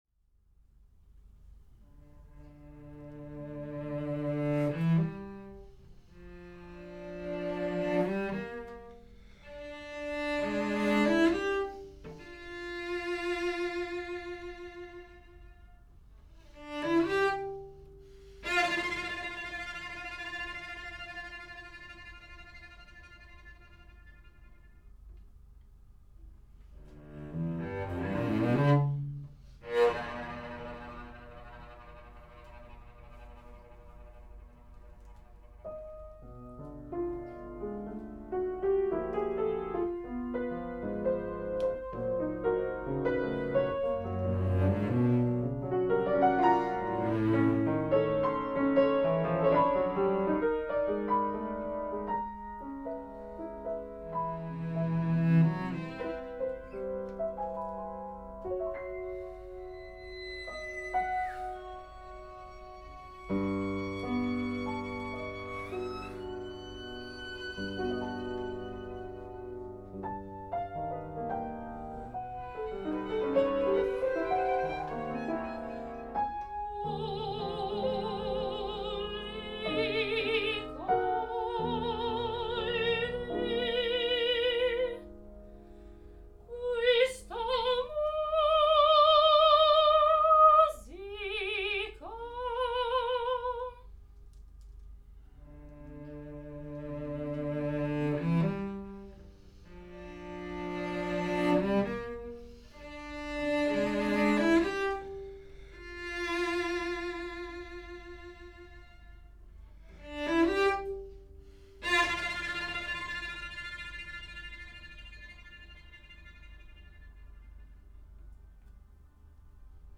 Pianoforte
Violoncello
Soprano
Tenore